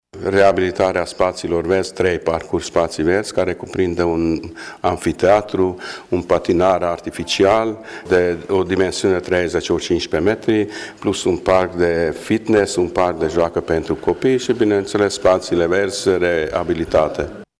Primarul din Băile Tușnad, Albert Tibor: